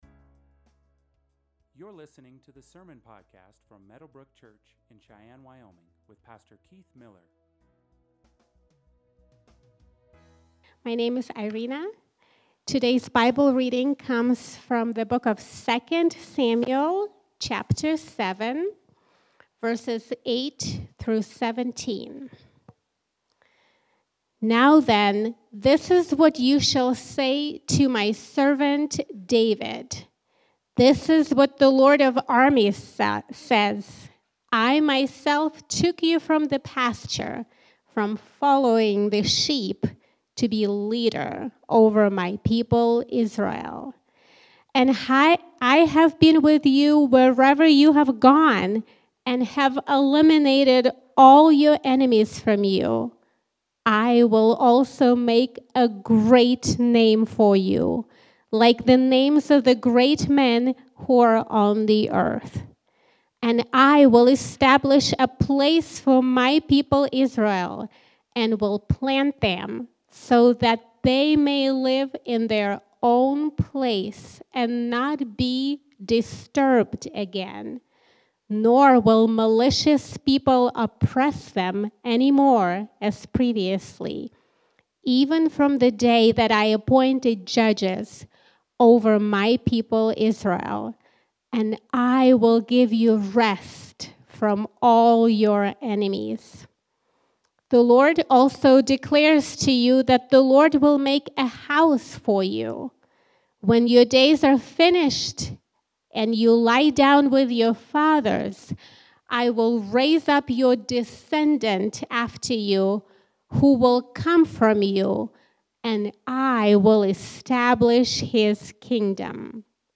Sermons | Meadowbrooke Church